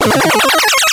Звук дополнительной жизни в игре (1up) (00:01)